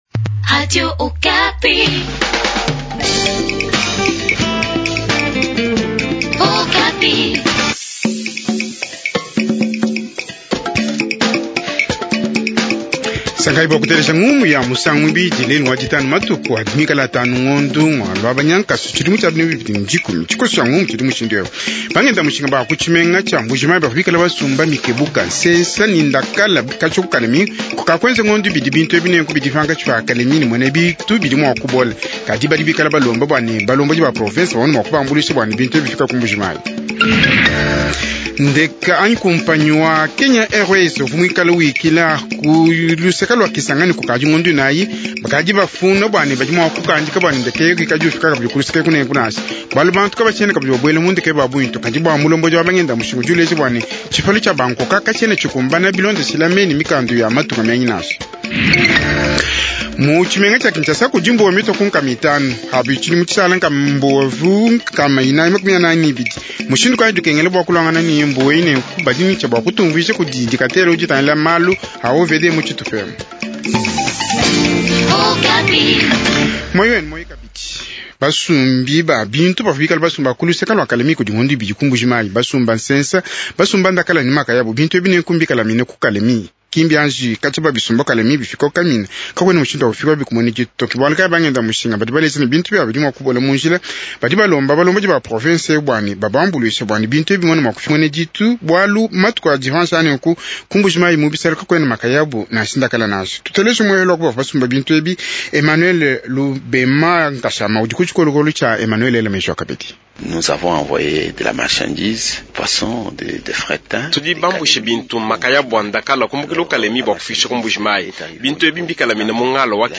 Jouirnal Tshiluba Soir